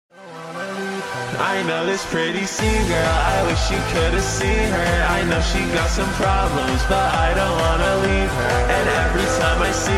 hehehe sound effects free download